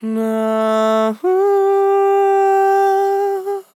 Categories: Vocals Tags: dry, english, fill, HEEE, LOFI VIBES, male, NAAA, sample
MAN-LYRICS-FILLS-120bpm-Am-15.wav